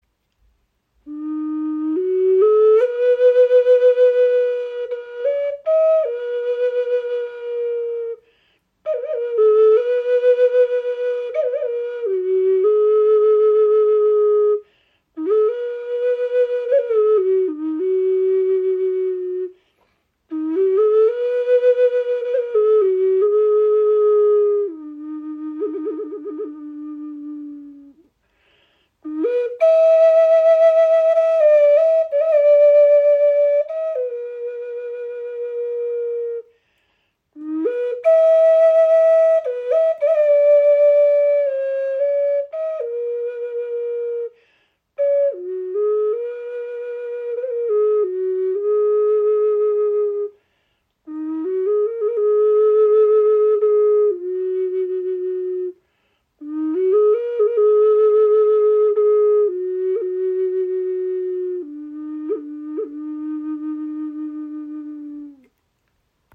Okarina aus Wurzelstück | F in 432 Hz | Pentatonische Stimmung | ca. 18 cm
Klein, handlich, klangvoll – eine Okarina mit Seele
In pentatonischer F Moll Stimmung auf 432 Hz gestimmt, entfaltet sie einen warmen, klaren Klang, der Herz und Seele berührt.
Trotz ihrer handlichen Grösse erzeugt sie einen angenehm tiefen und warmen Klang – fast ebenbürtig zur nordamerikanischen Gebetsflöte.